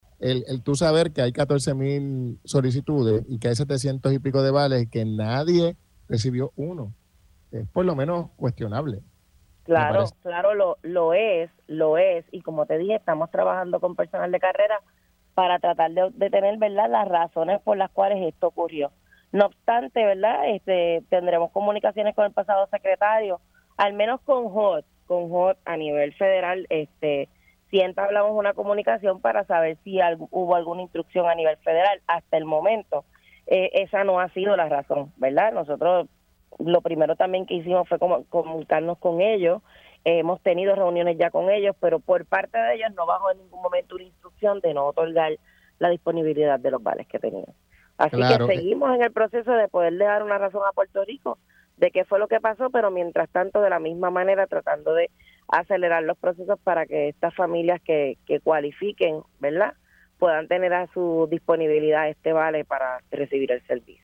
No obstante, tan pronto nosotros llegamos a la agencia, estuvimos reuniéndonos con cada una de las áreas, viendo de qué había disponibilidad, observando los servicios importantes de la gente y descubrimos con el área de sección 8 que en 2024 no se otorgó un solo vale, habiendo disponible casi 700 vales para 700 familias, para ser específica, 686 para familias en Puerto Rico“, indicó en entrevista para Pega’os en la Mañana.